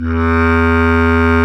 Index of /90_sSampleCDs/Roland LCDP04 Orchestral Winds/CMB_Wind Sects 1/CMB_Wind Sect 5
WND BSCLARE2.wav